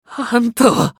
男性
熱血系ボイス～日常ボイス～
【名前を呼ぶ2（悲しい）】